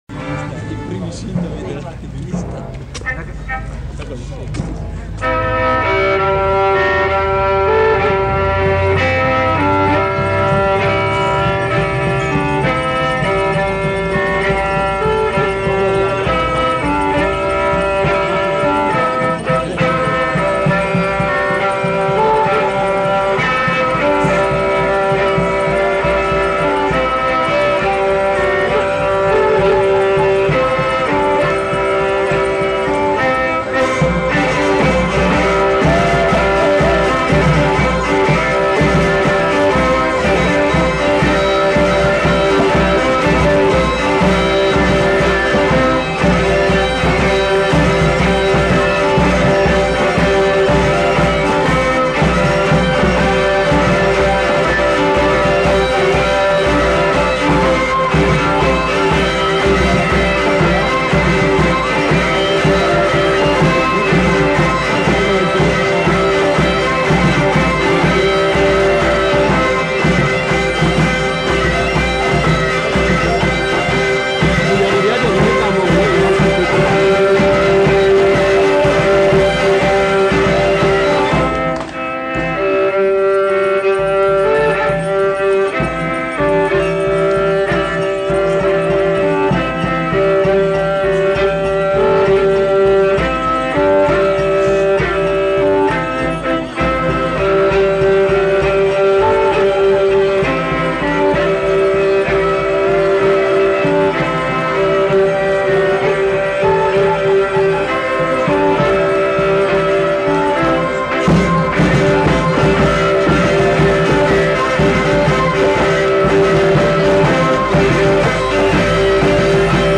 Aire culturelle : Pays d'Oc
Lieu : Pinerolo
Genre : morceau instrumental
Instrument de musique : violon ; vielle à roue ; boha ; percussions
Danse : courante